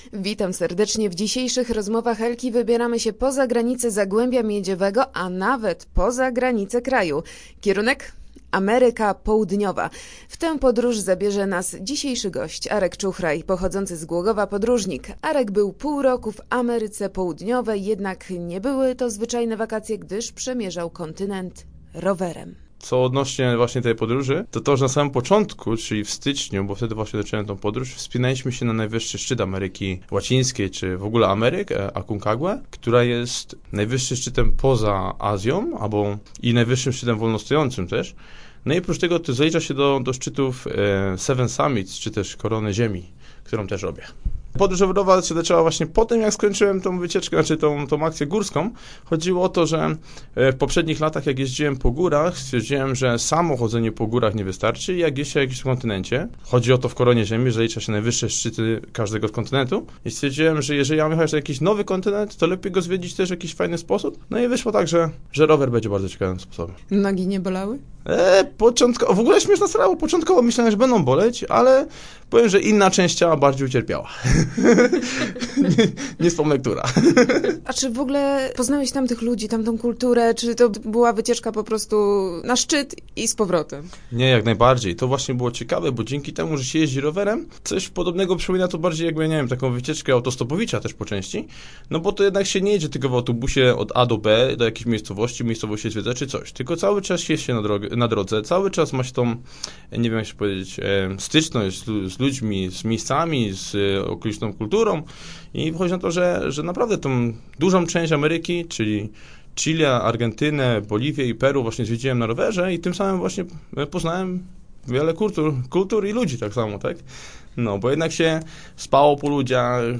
W studiu o podróżach